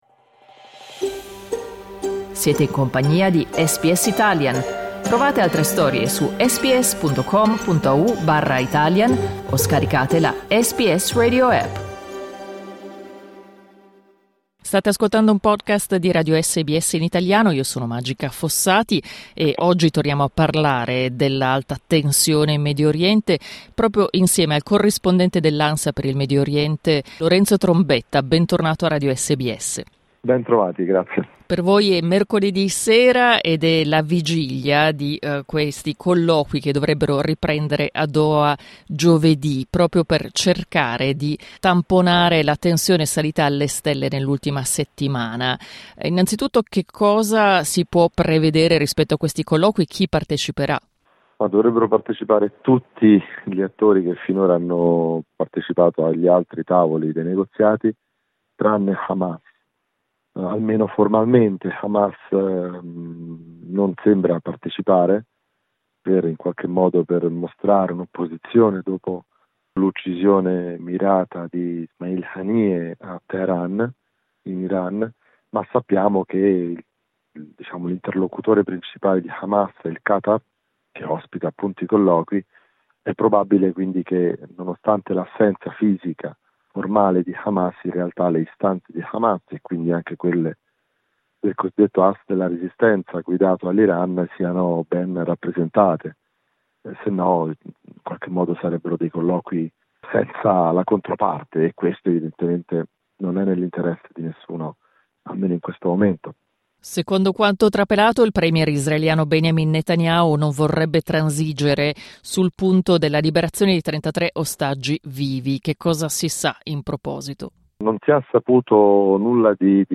Clicca sul tasto "play" in alto per ascoltare l'intervista Ascolta SBS Italian tutti i giorni, dalle 8am alle 10am.